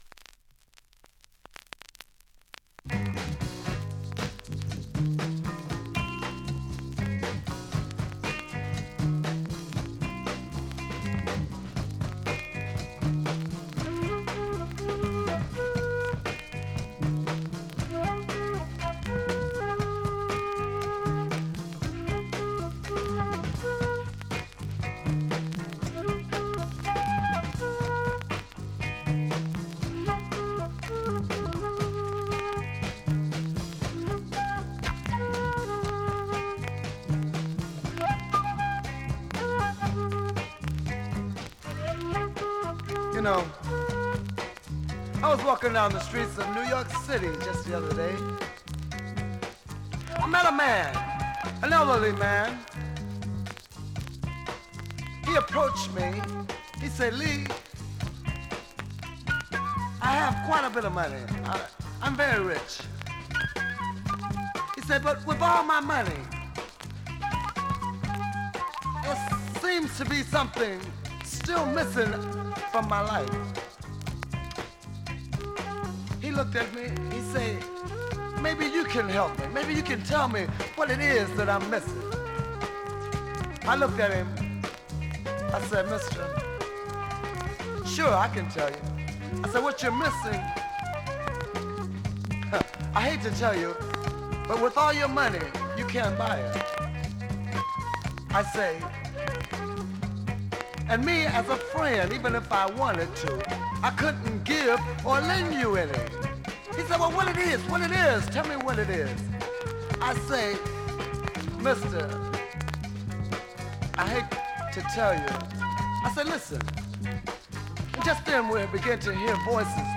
◆盤質Ａ面/VG ◆盤質Ｂ面/G薄いスレ多め B面周回プツ出ますが ストレスは少ないです。
(Recorded Live)